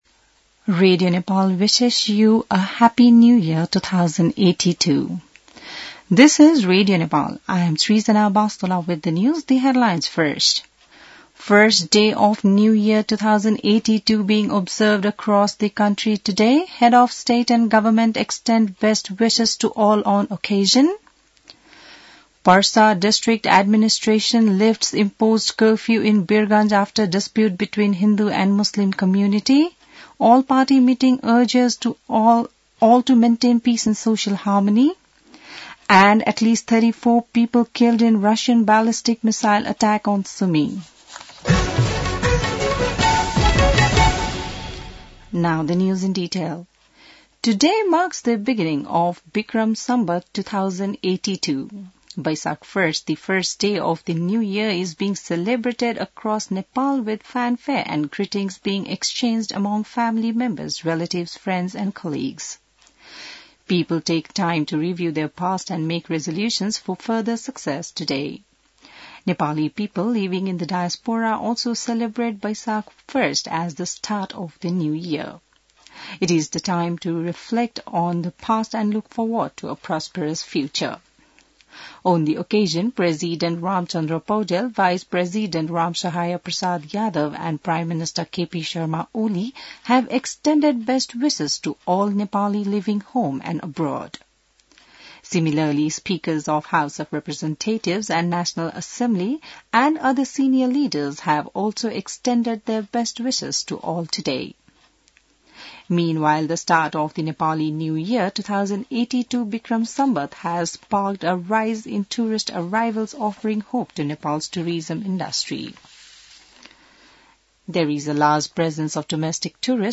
बिहान ८ बजेको अङ्ग्रेजी समाचार : १ वैशाख , २०८२